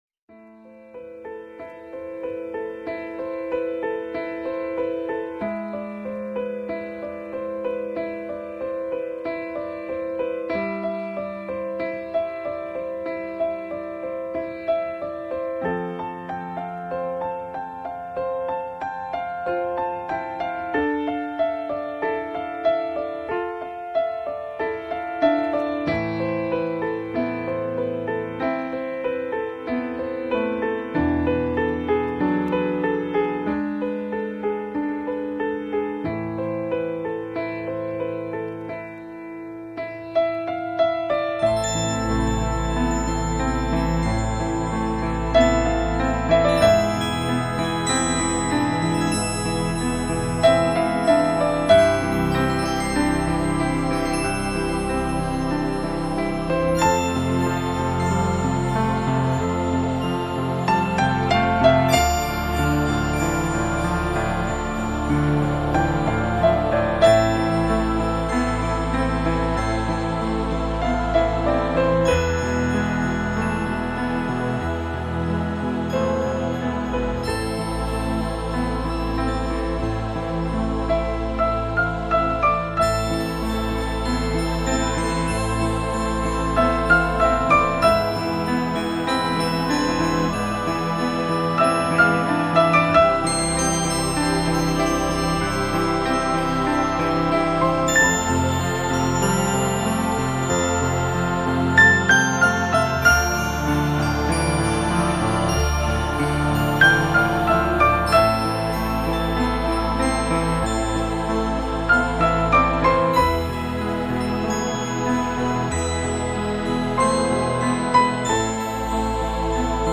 还有请班主原谅，我的曲子是纯音乐，所以没有歌词，所以请谅解，不要删我贴我，怕怕......